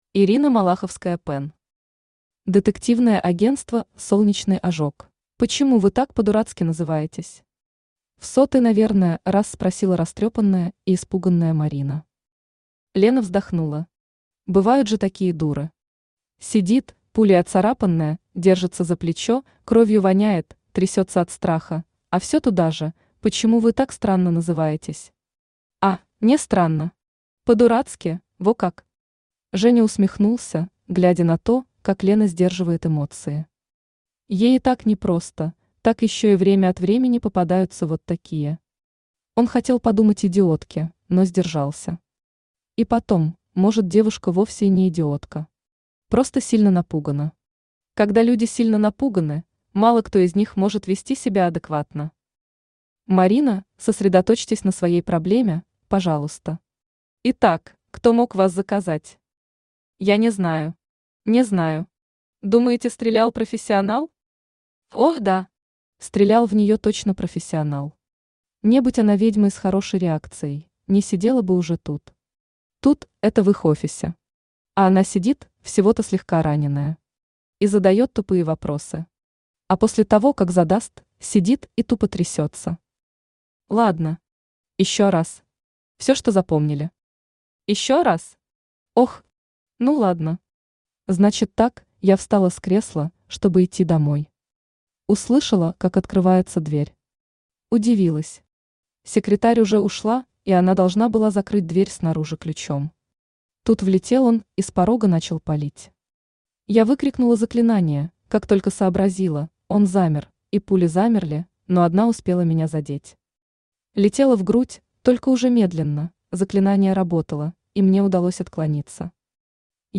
Аудиокнига Детективное агентство «Солнечный ожог» | Библиотека аудиокниг
Aудиокнига Детективное агентство «Солнечный ожог» Автор Ирина Малаховская-Пен Читает аудиокнигу Авточтец ЛитРес.